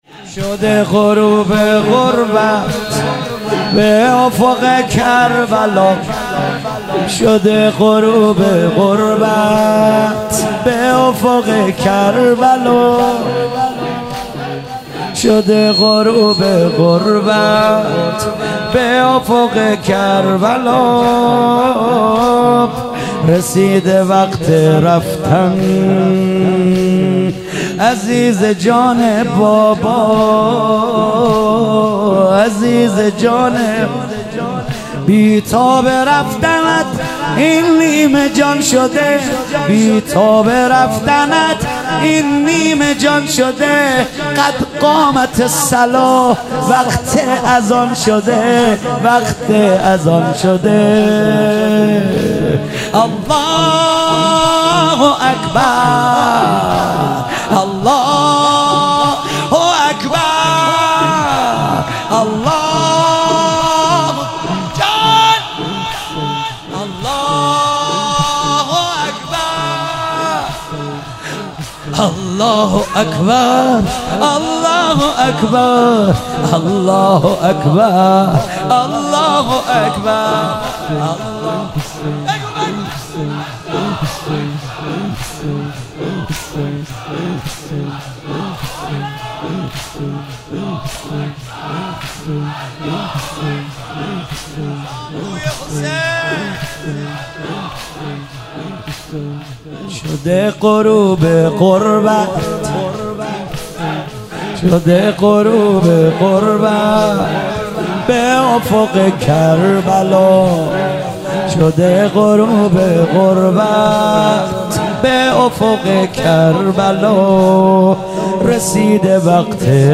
شب هشتم محرم 97 - زمینه - شده غروب غربت به افق کربلا